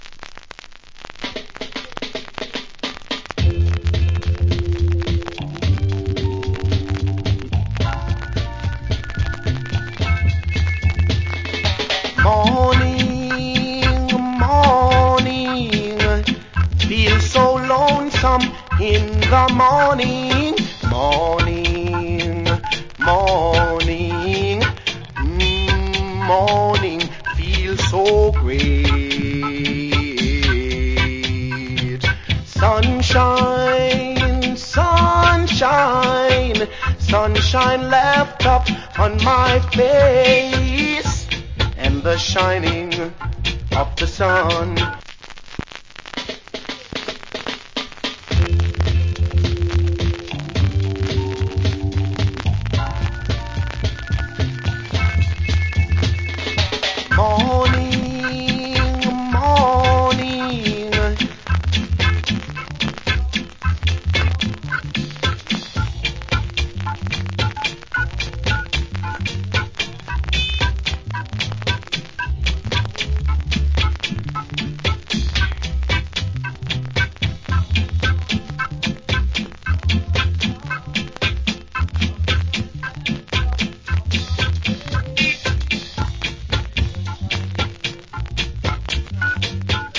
Cool Reggae Vocal.